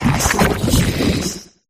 grapploct_ambient.ogg